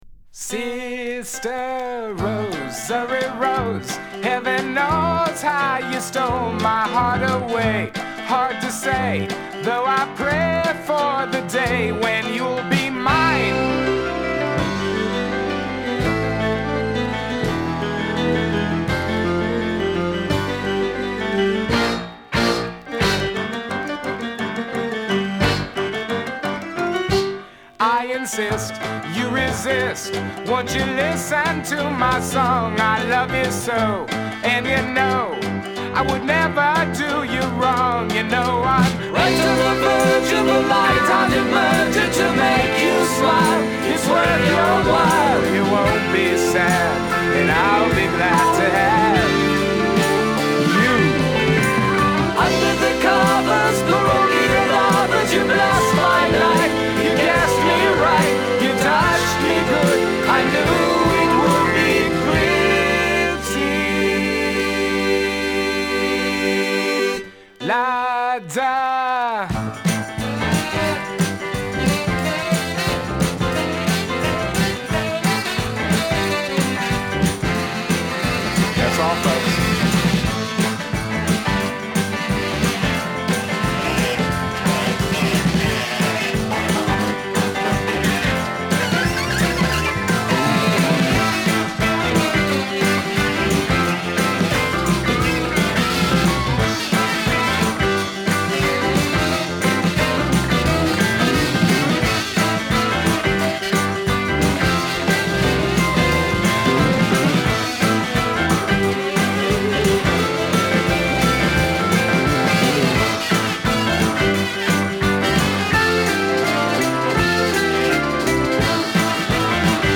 ハードエッジ・ギターや手数の多いドラミングがThe Whoを思わせるハード・ポップ・ロック。